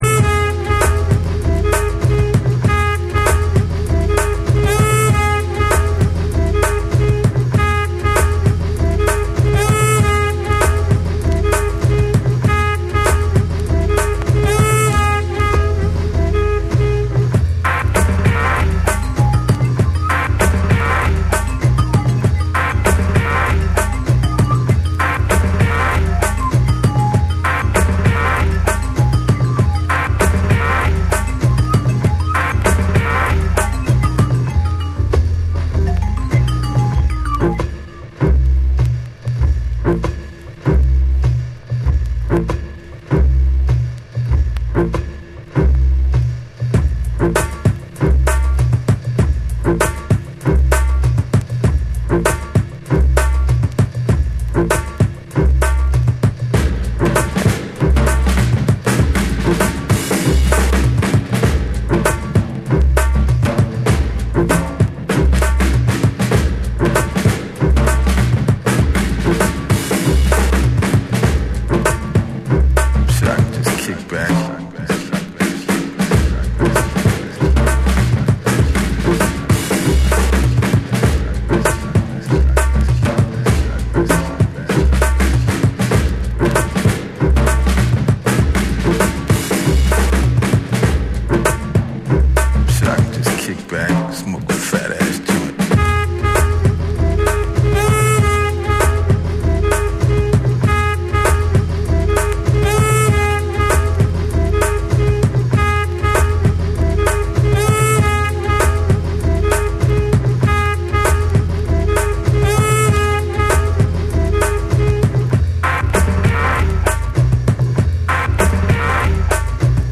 BREAKBEATS / JUNGLE & DRUM'N BASS